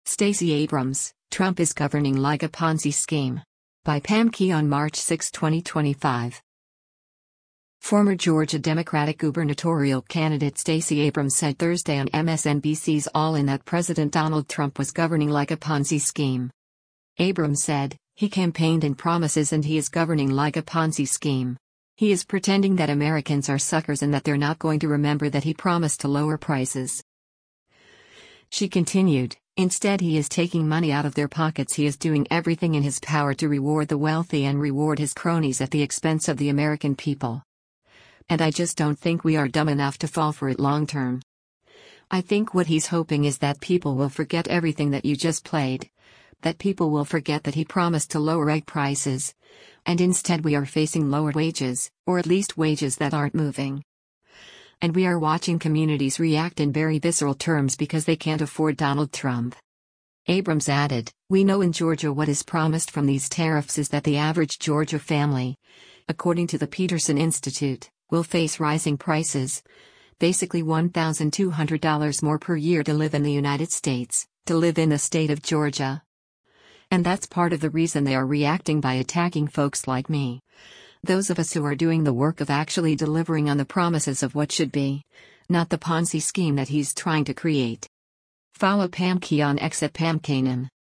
Former Georgia Democratic gubernatorial candidate Stacey Abrams said Thursday on MSNBC’s “All In” that President Donald Trump was “governing like a Ponzi scheme.”